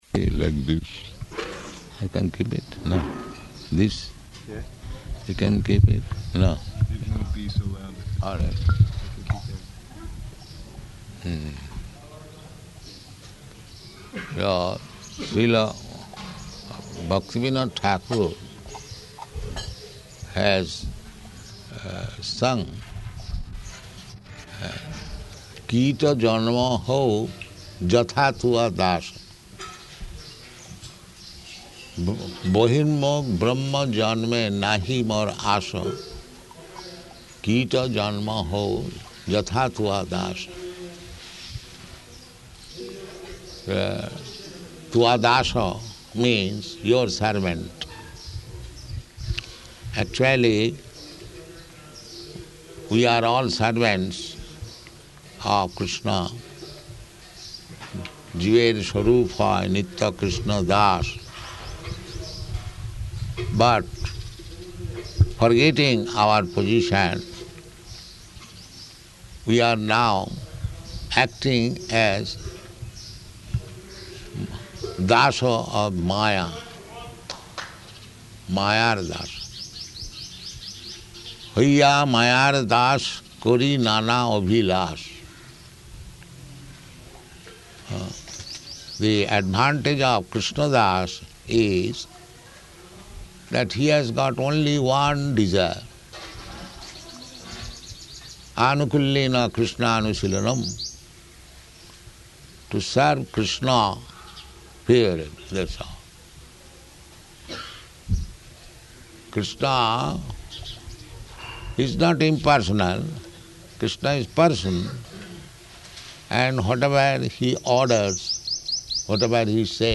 Life Member House Lecture
Life Member House Lecture --:-- --:-- Type: Lectures and Addresses Dated: April 14th 1975 Location: Hyderabad Audio file: 750414L2.HYD.mp3 Prabhupāda: [indistinct] this, I can keep it, no?